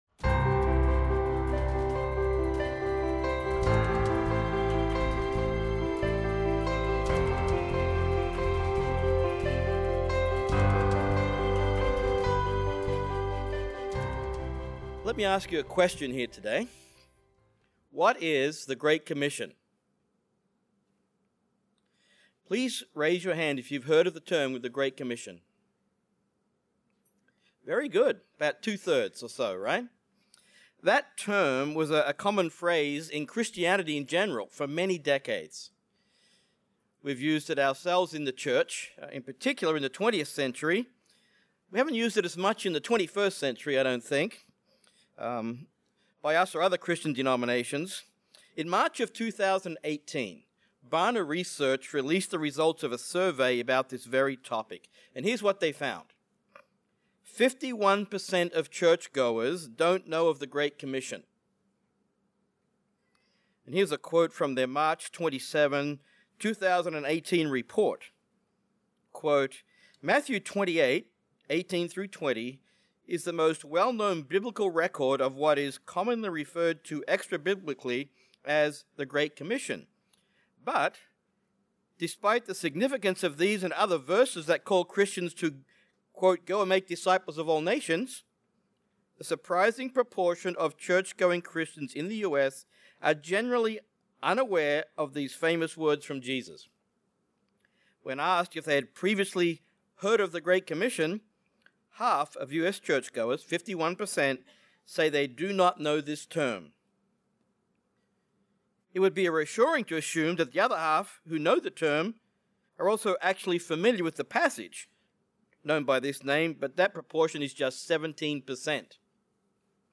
This message takes a look at the gospel going to Europe in the first century and then compares it to the gospel in the 21st century—two millennia later.